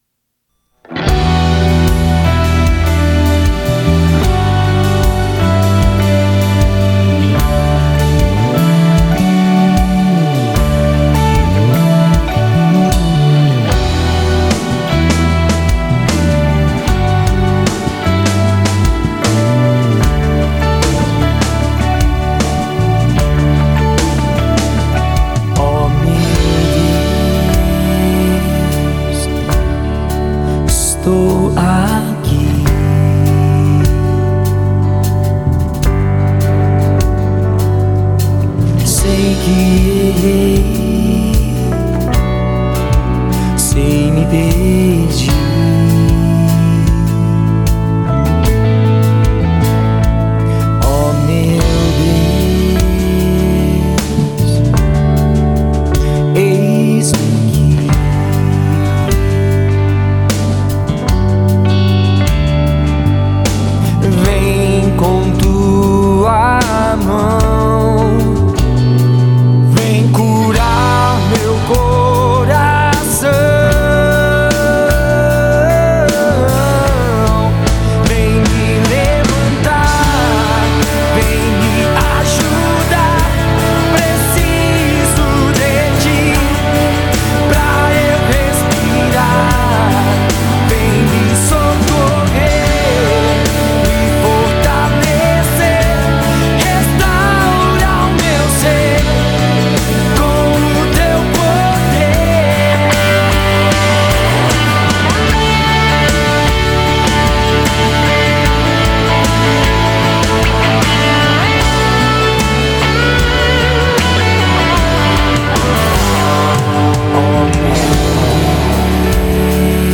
vocal e gaita
guitarra
teclado
bateria
contrabaixo